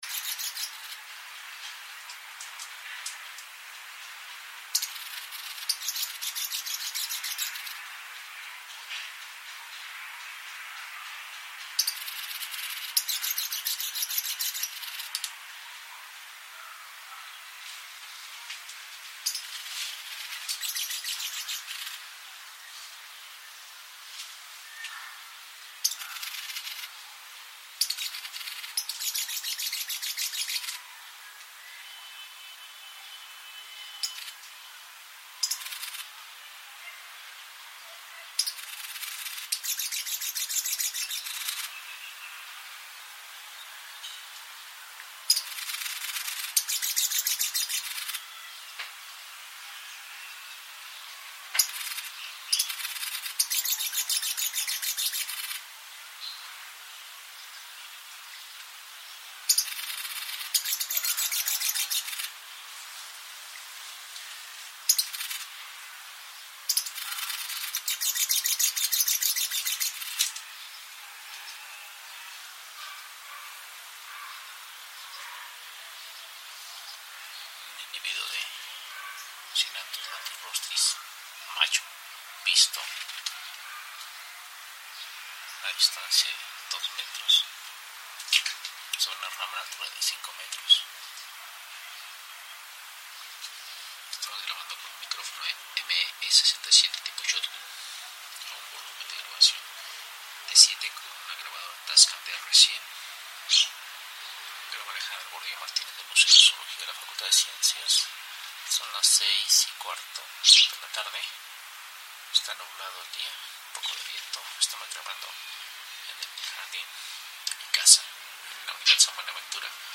Este acervo cuenta en la actualidad con aproximadamente 6000 cortes de 400 especies obtenidas durante el trabajo de campo de diversos proyectos, especialmente en Chiapas, Oaxaca, Guerrero, Veracruz, Puebla, Sinaloa, Jalisco y Yucatán.
Canto de colibrí pico ancho (Cynanthus latirostris) macho.